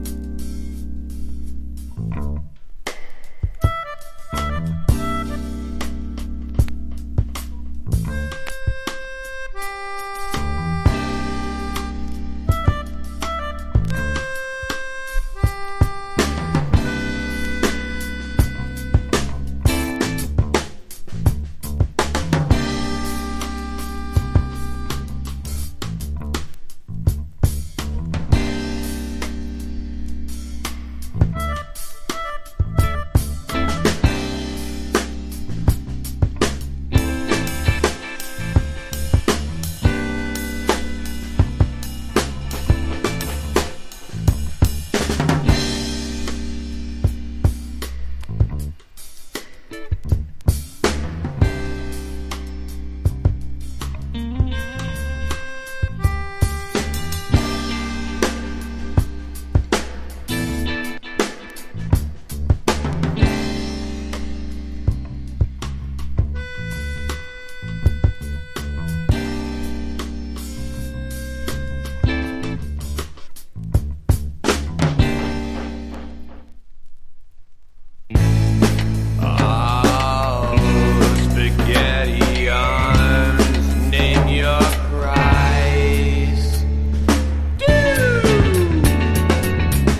これはハマる！USローファイ節＋ブラックミュージックなチルアウト盤！
ALTERNATIVE / GRUNGE